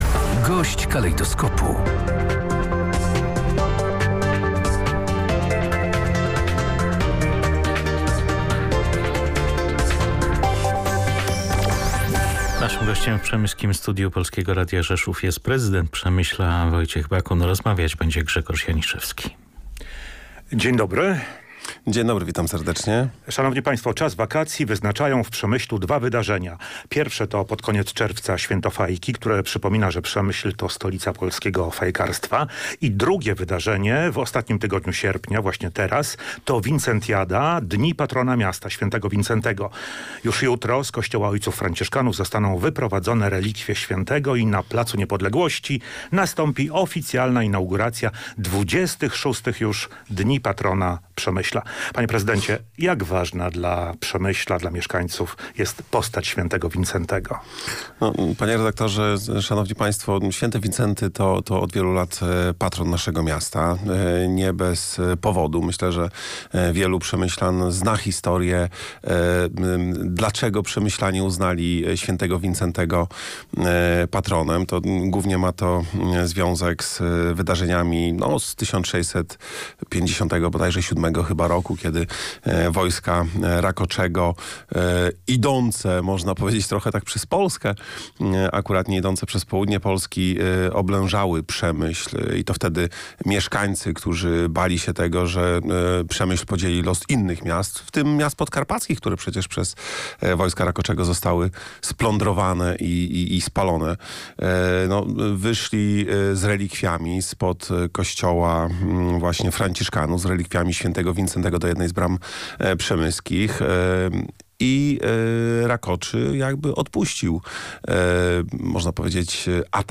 – powiedział na antenie Polskiego Radia Rzeszów prezydent Przemyśla Wojciech Bakun.